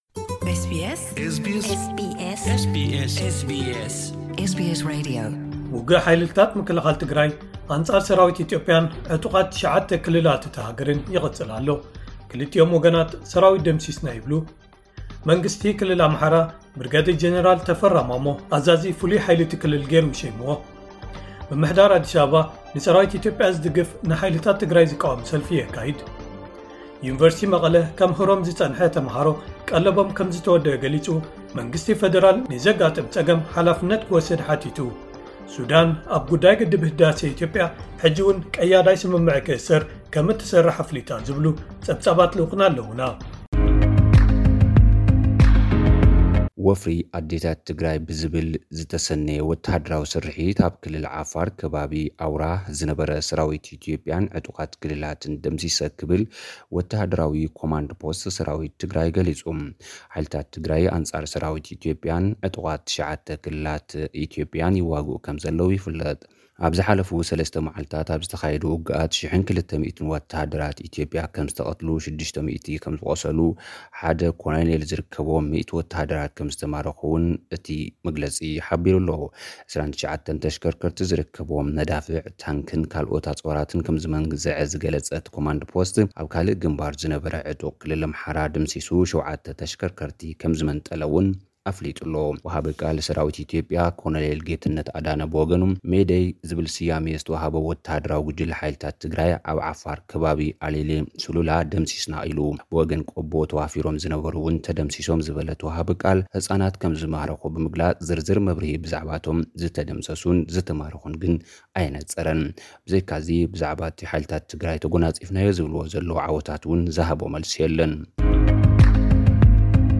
ጸብጻባት ዜና